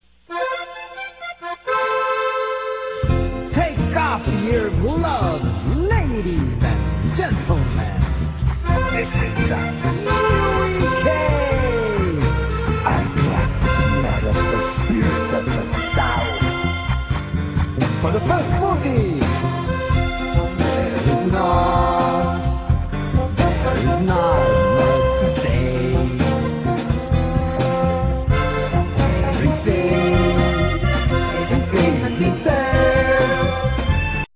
latino, cueca